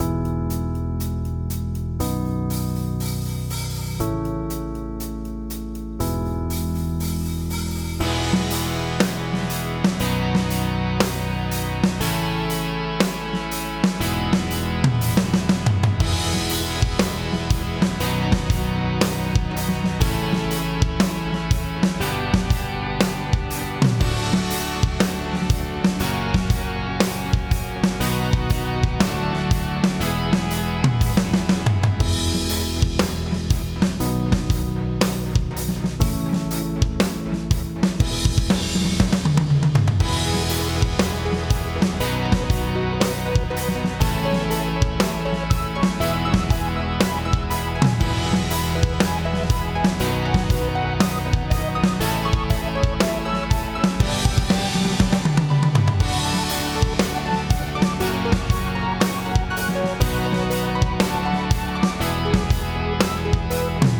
אבל אולי הריברב נותן תחושה של בריחה מהביט.